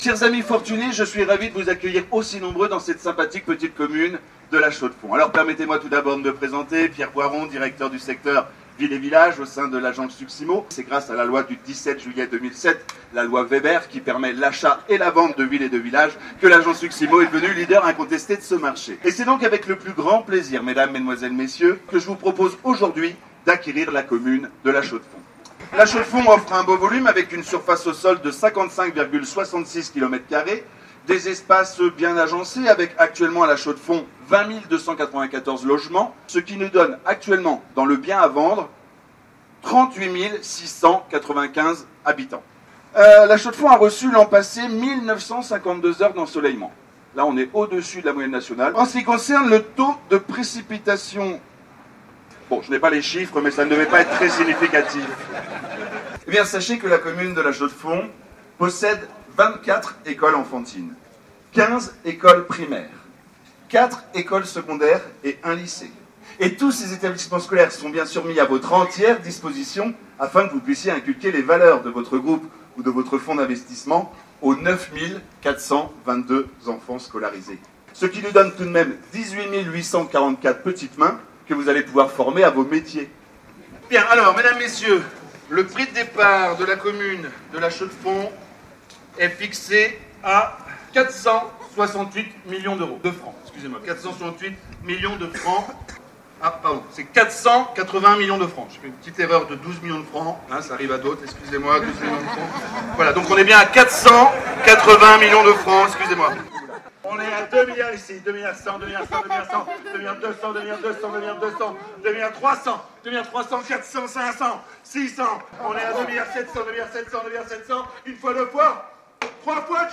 Reportage !